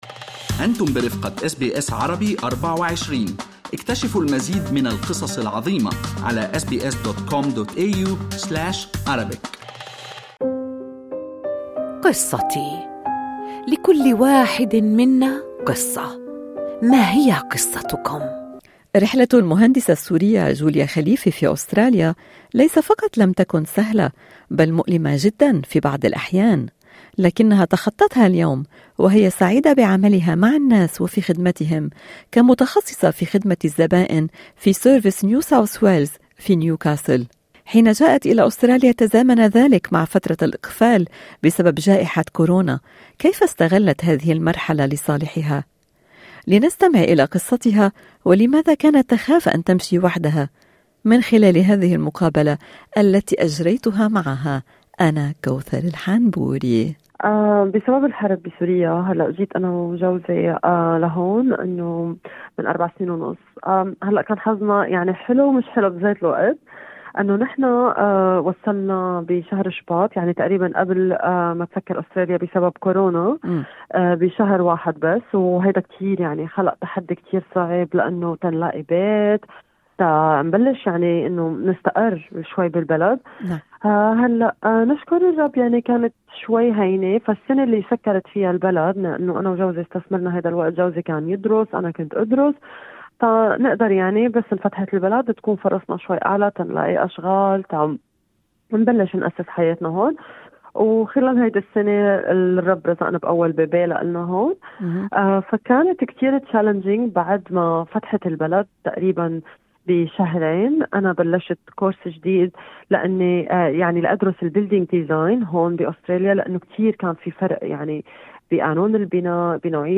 قصتي